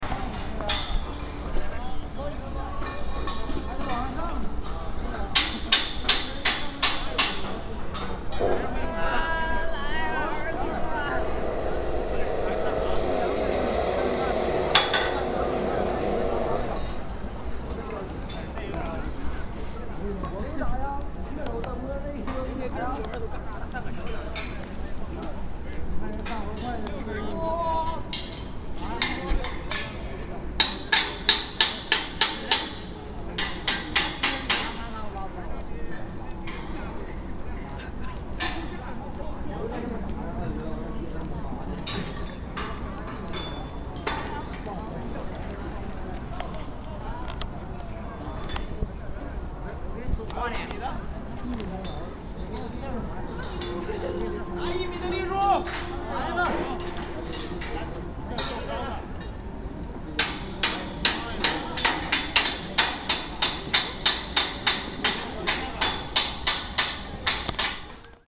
Scaffolding at the First Pass Under Heaven
Scaffolding being erected at the the first pass in the east part of the Ming Dynasty Great Wall of China.